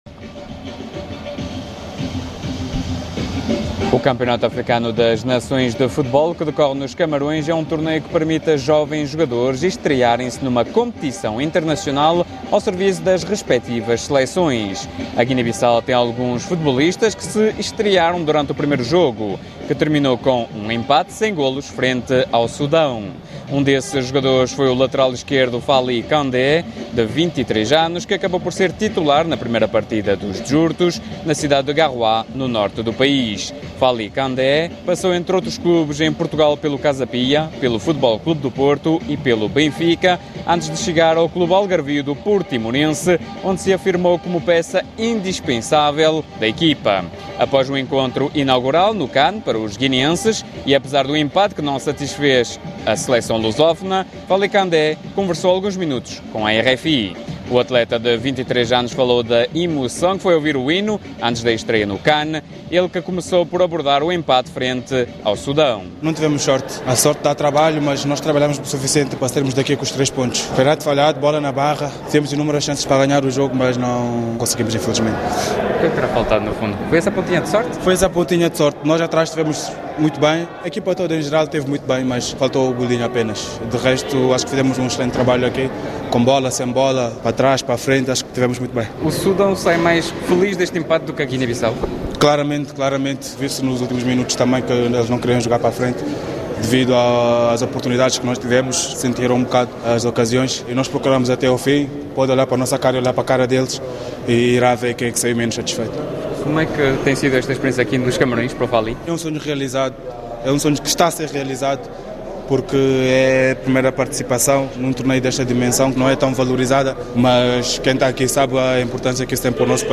Após o encontro inaugural no CAN para os guineenses, e apesar do empate que não satisfez a selecção lusófona, Fali Candé conversou alguns minutos com a RFI.